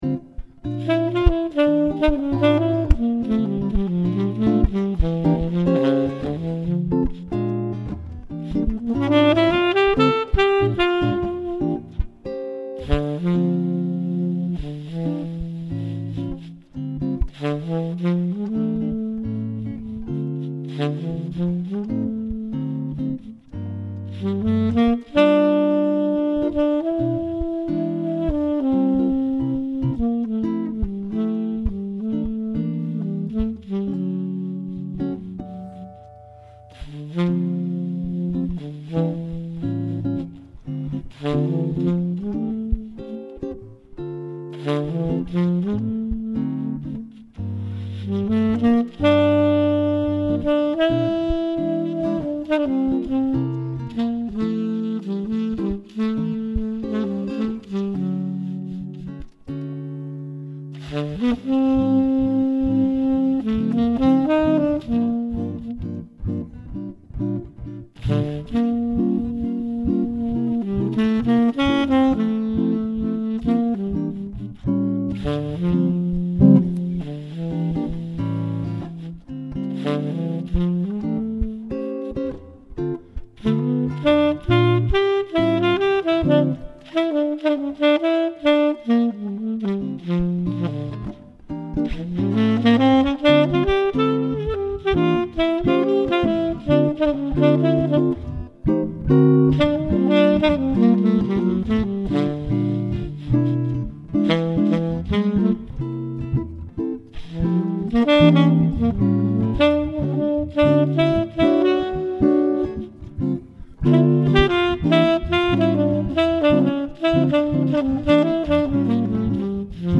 Saxophon
Gitarre